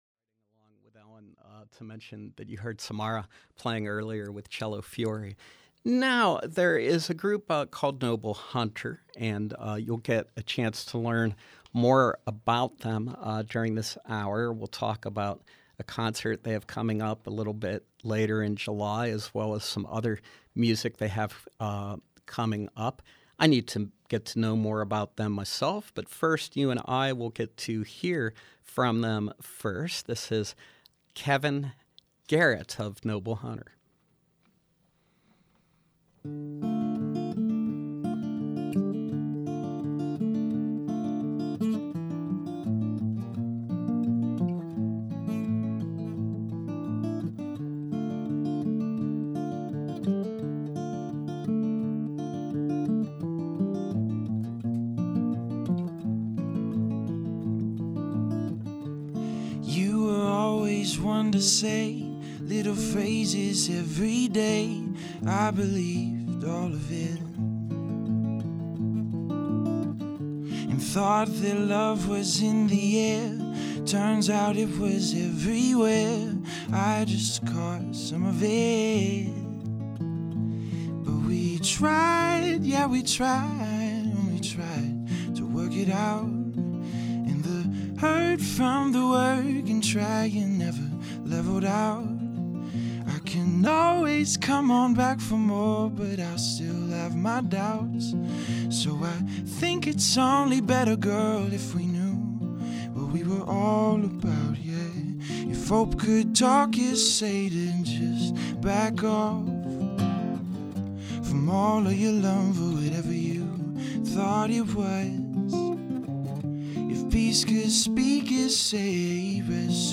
Live folk-rock music